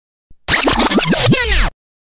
Hiệu ứng âm thanh RECORD SCRATCH #2 mp3 - Tải hiệu ứng âm thanh để edit video
Bạn đang tìm hiệu ứng âm thanh RECORD SCRATCH #2 để edit video ?